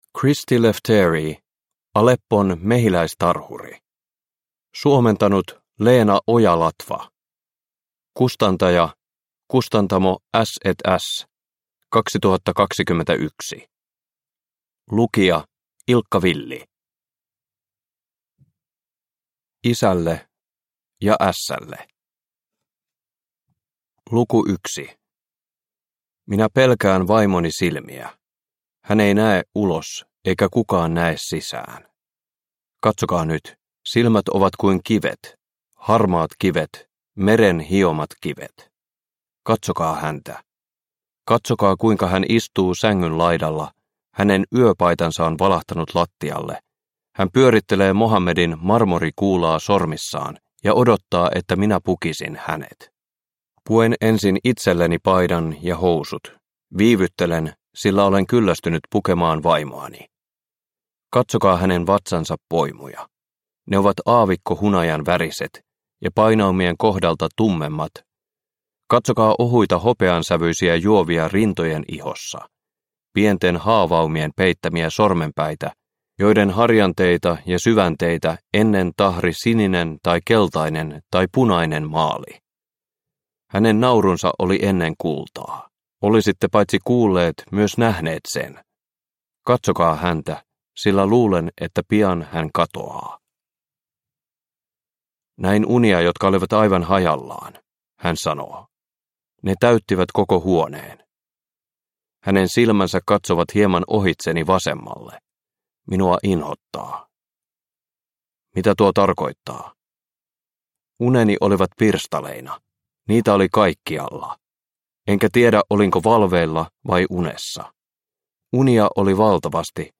Aleppon mehiläistarhuri – Ljudbok – Laddas ner